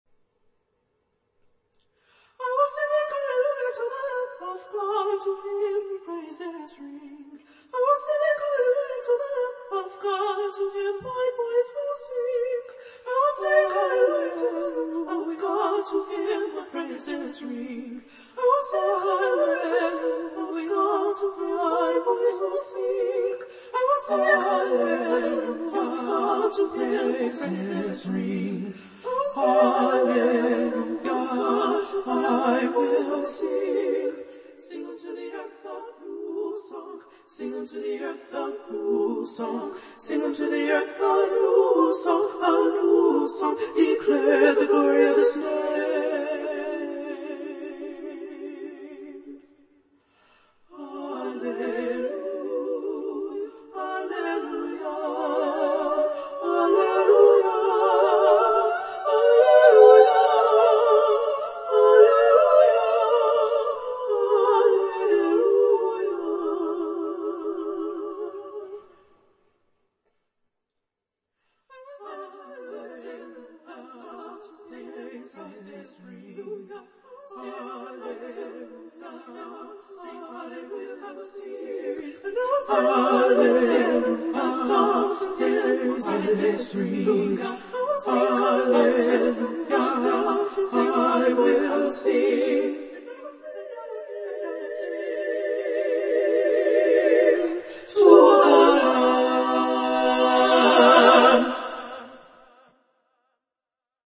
Voicing: SSA Choir